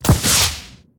bl_rocketlauncher_shoot.ogg